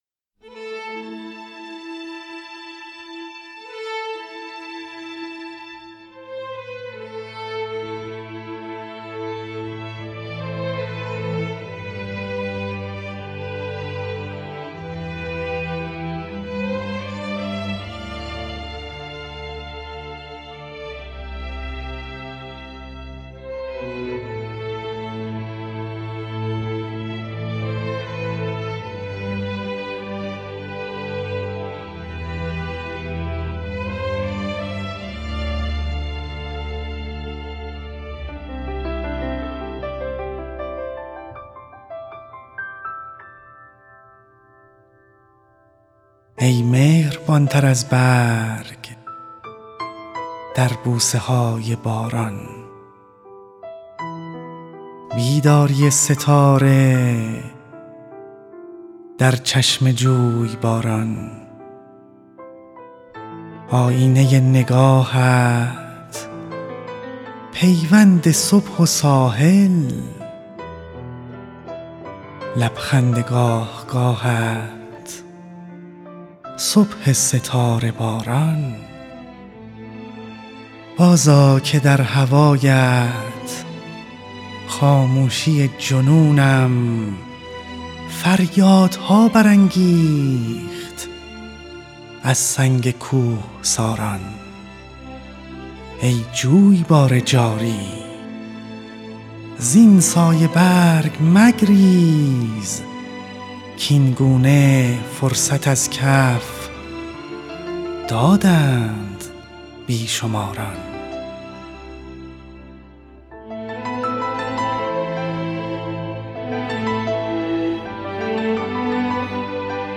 «بوسه‌های باران» یکی از سروده‌های زیبای محمدرضا شفیعی‌کدکنی است که در پروژه «پرواز خیال» به صورت موسیقی گفتار اجرا شده است.
در این مجموعه سروده‌هایی مهم در ادبیات ایران و جهان انتخاب شده و با همراهی موسیقی خوانده شده‌اند.
هر اجرا هم با موسیقی متناسبی همراه شده است.